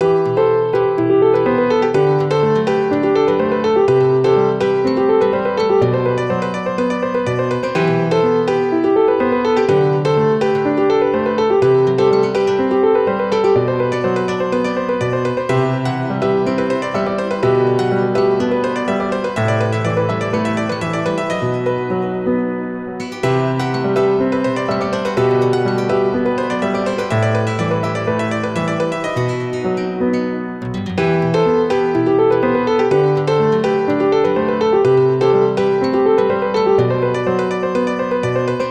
Piano -tipo español- (bucle)
piano
melodía
repetitivo
rítmico
sintetizador